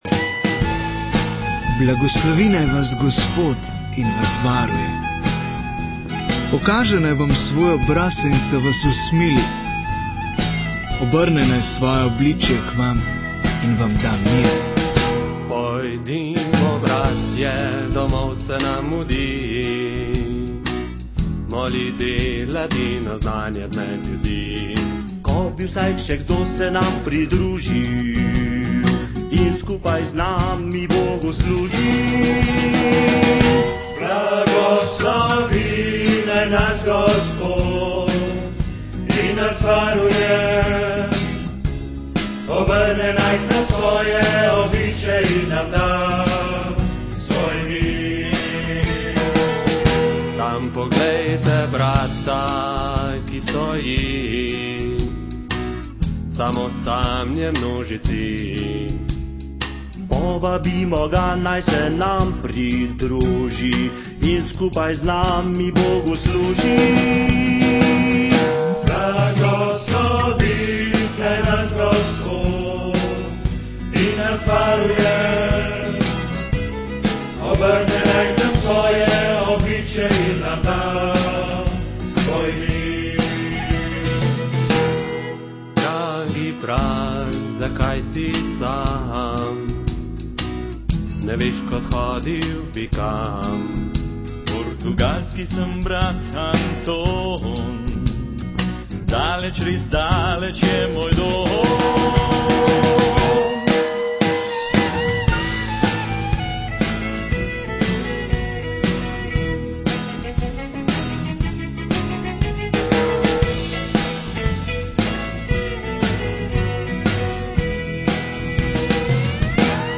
Musical o svetem Antonu Padovanskem